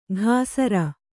♪ ghāsara